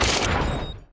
tesla-turret-deactivate.ogg